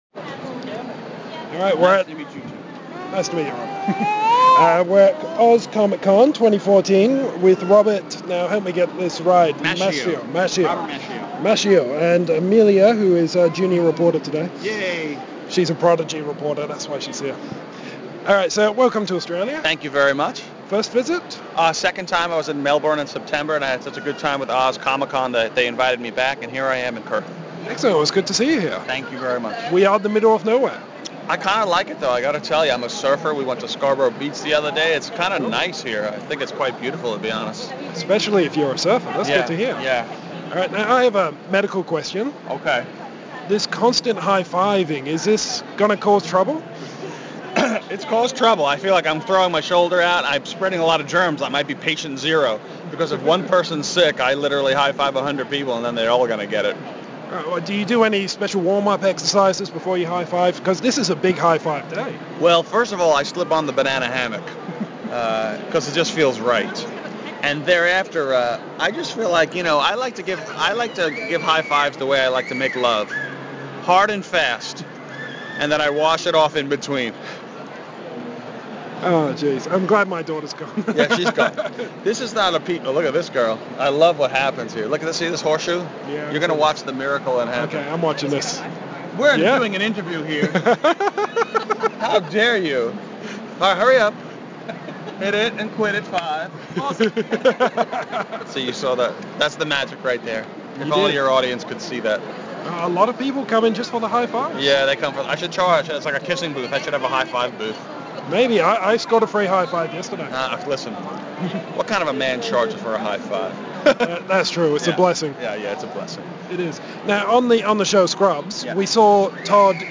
The audio is in two parts (required for high-five sound effects) and the transcript follows.
Category : Interviews